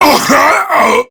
BbDeathPrimeGrineerMale0390_en.ogg